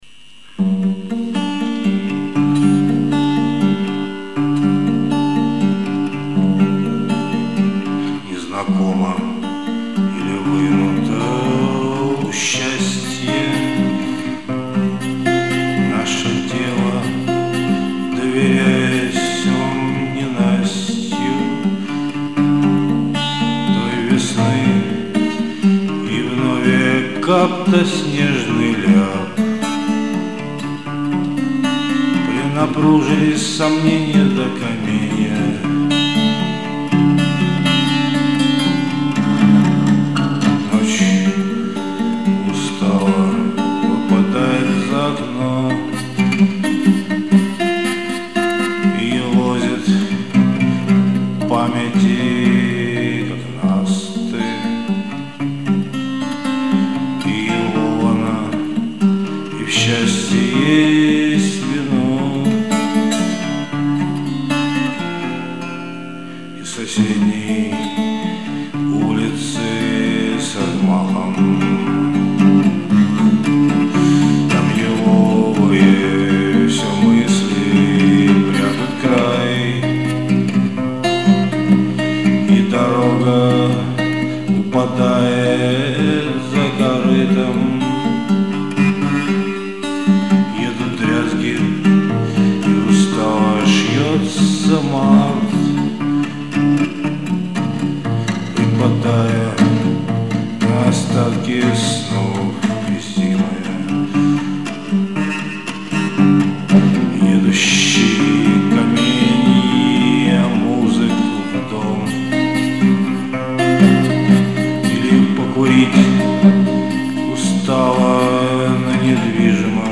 ----песни мои---------------------------------
летом записывал, ((2024г)